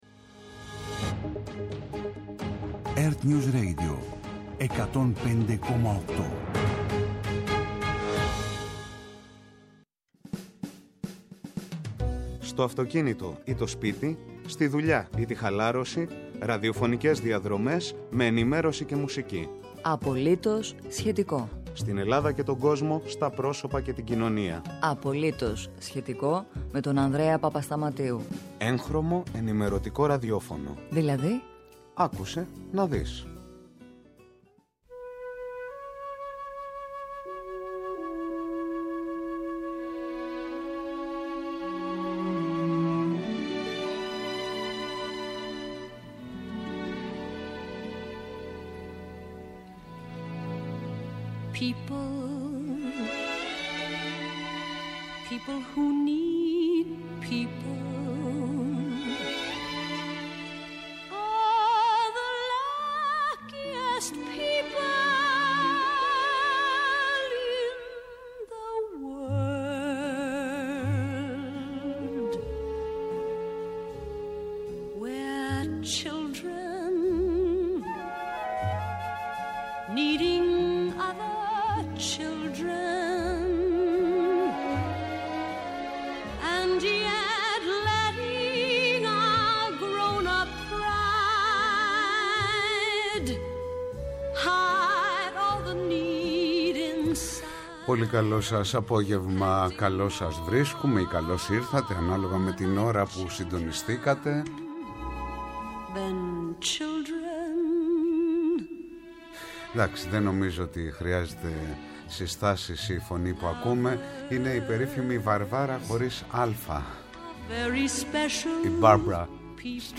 –Από το 11ο Οικονομικό Φόρουμ των Δελφών